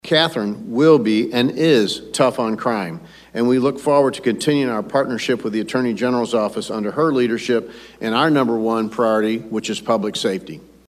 Jefferson City, Mo. (KFMO) - During a press conference Tuesday at the Missouri State Capitol, Governor Mike Kehoe announced the appointment of Catherine L. Hanaway as Missouri’s next Attorney General.